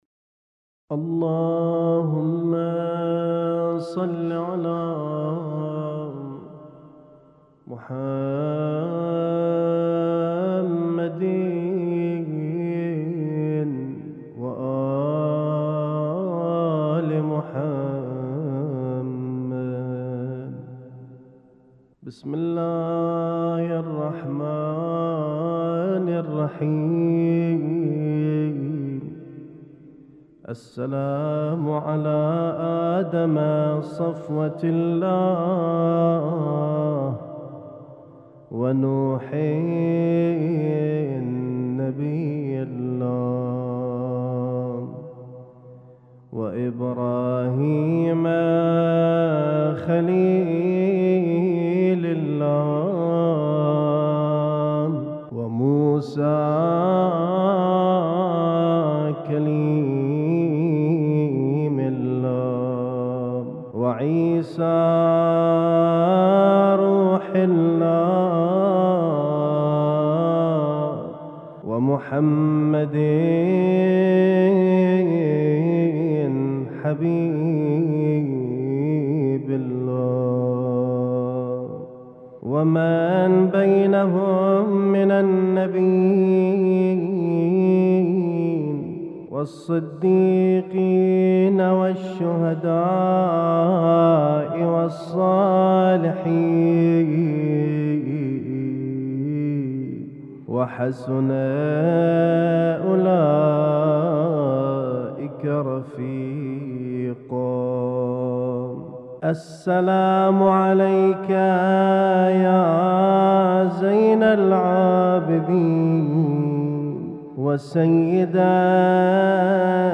اسم التصنيف: المـكتبة الصــوتيه >> الزيارات >> الزيارات الخاصة